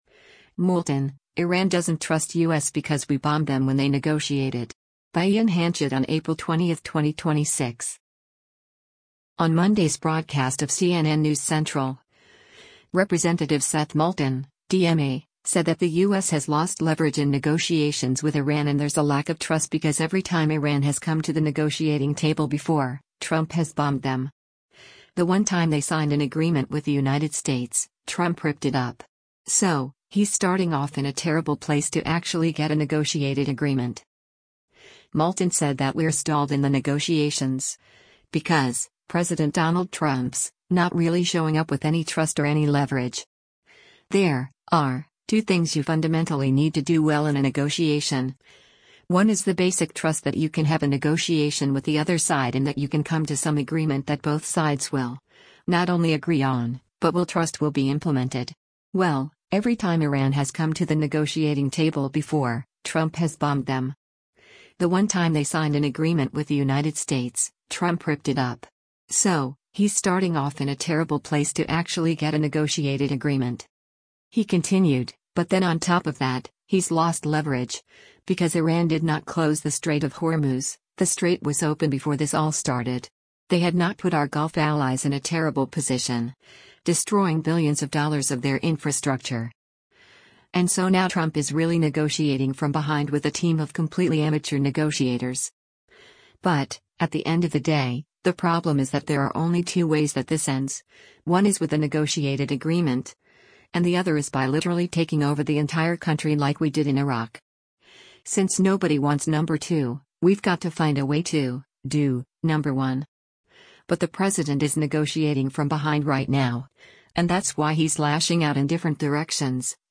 On Monday’s broadcast of “CNN News Central,” Rep. Seth Moulton (D-MA) said that the U.S. has lost leverage in negotiations with Iran and there’s a lack of trust because “every time Iran has come to the negotiating table before, Trump has bombed them.